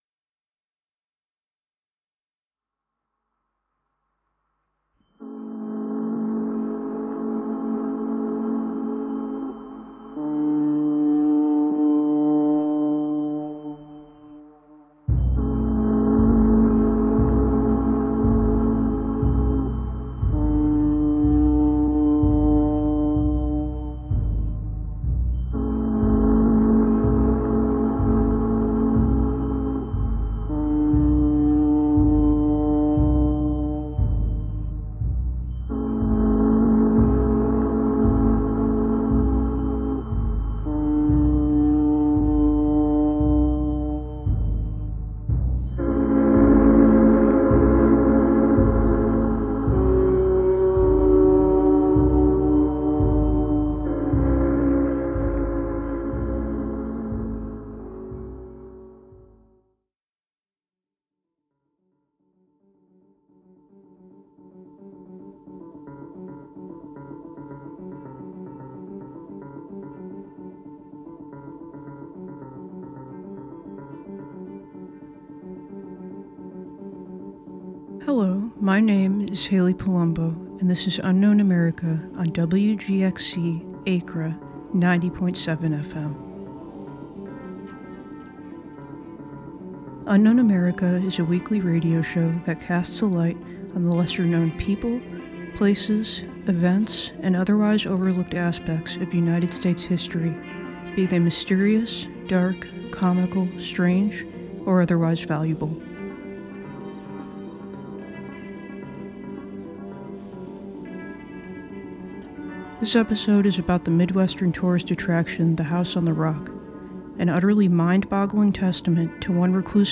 "Unknown America" is a show that dives into places, people, events, and other aspects of American history that often go overlooked. Through occasional interviews, on-site reporting, frantically obsessive research, and personal accounts, the listener will emerge out the other side just a bit wiser and more curious about the forgotten footnotes of history that make America fascinating, curious, and complicated.